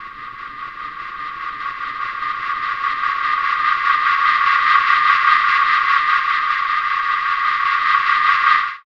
Index of /90_sSampleCDs/Chillout (ambient1&2)/09 Flutterings (pad)
Amb1n2_p_flutter.wav